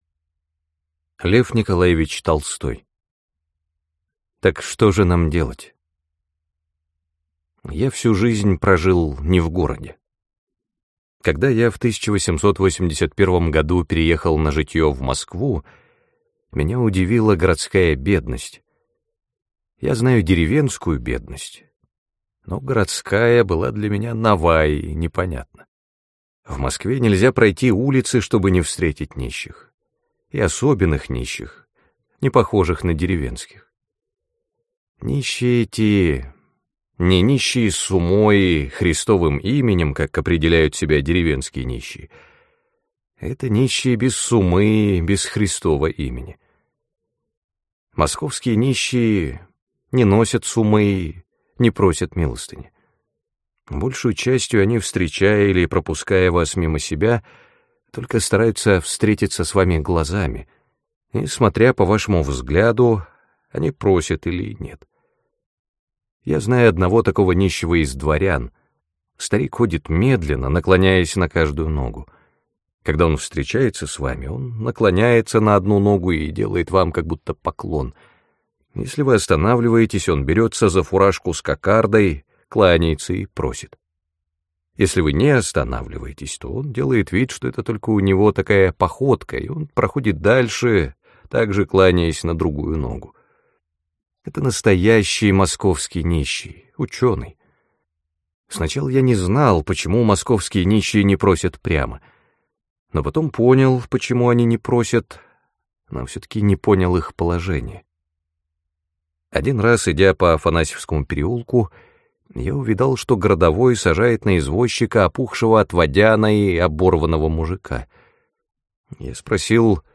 Аудиокнига Так что же нам делать?